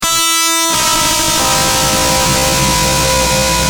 Screamb4.wav